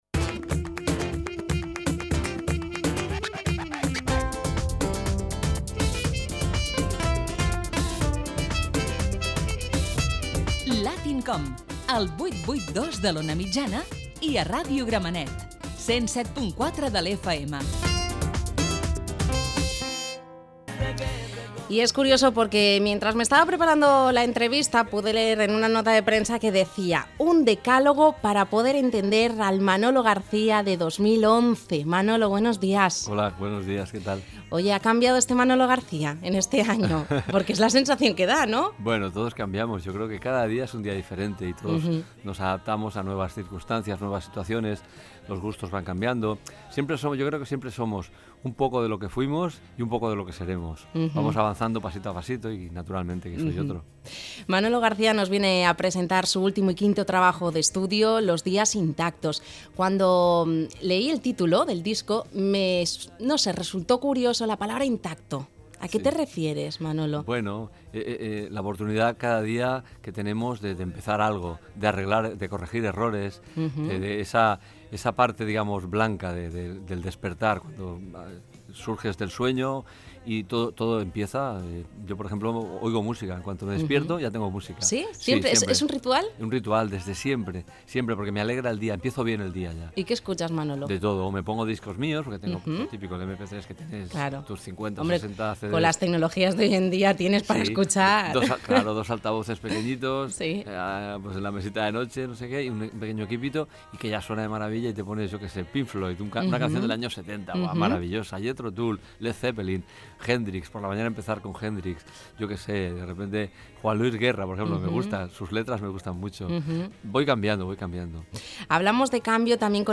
Identificació de l'emissora i de Latin COM, entrevista al cantant Manolo García
Entreteniment